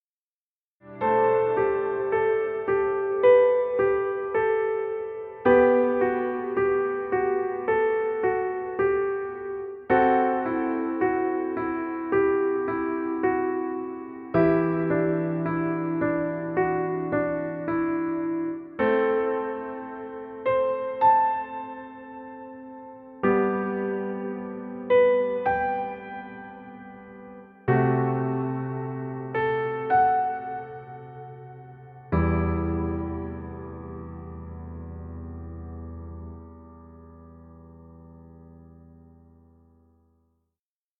Stock Music.